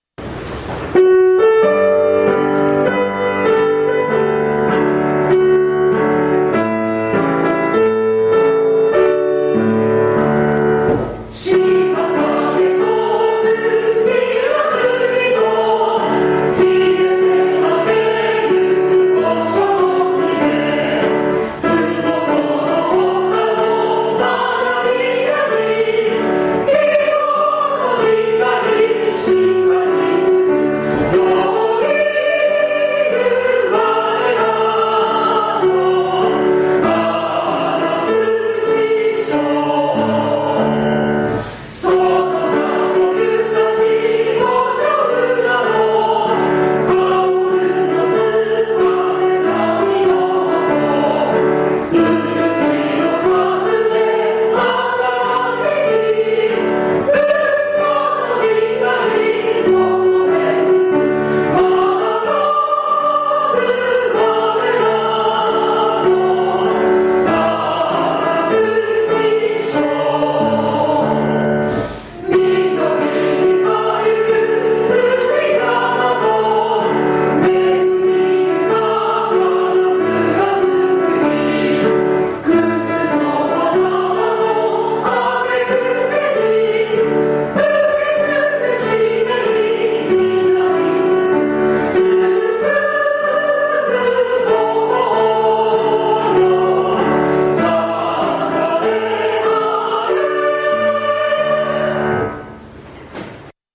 現行政区  旧行政区 No 小学校名  校歌楽譜・歌詞・概要  校歌音源（歌・伴奏） 　　備考
aragutisho_kouka_gattsho.mp3